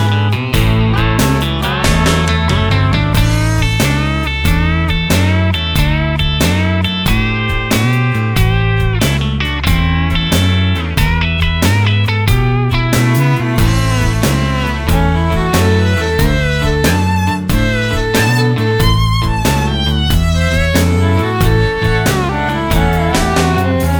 no Backing Vocals Country (Male) 2:22 Buy £1.50